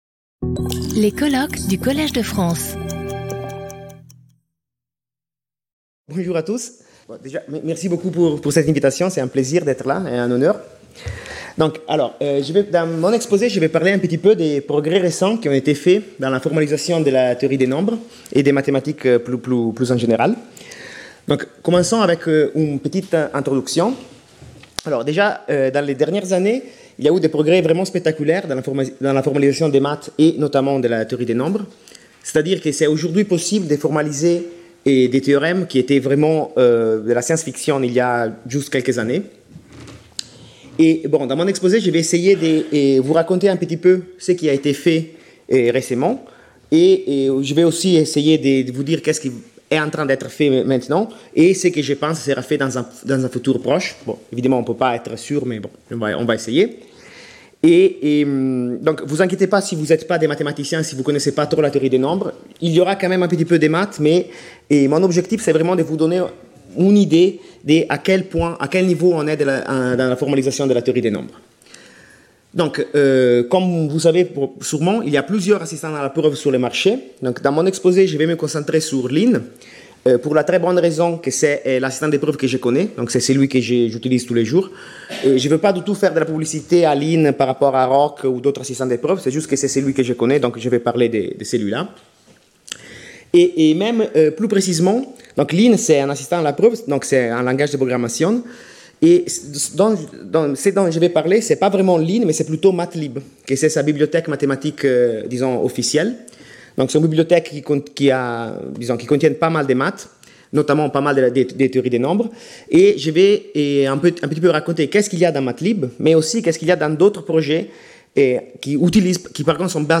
Colloque